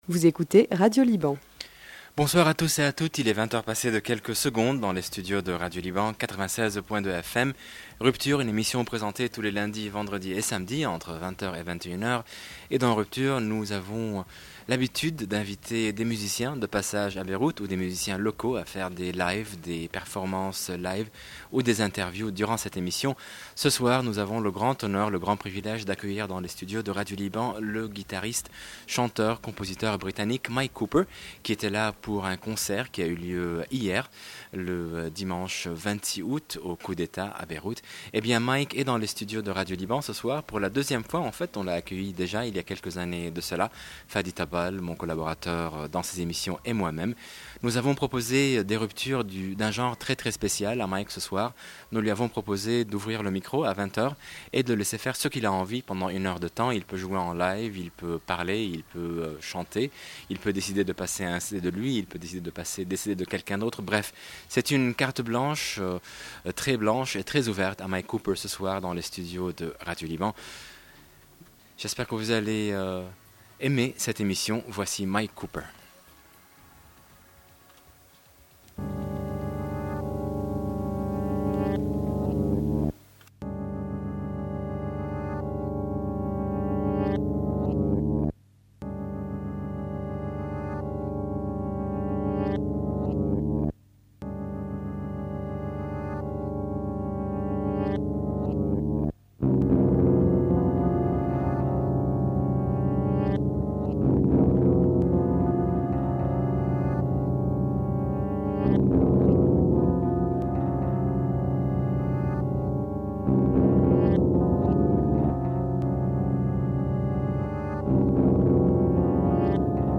Experimental folk